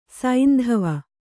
♪ saindhava